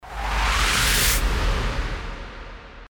FX-1559-WIPE
FX-1559-WIPE.mp3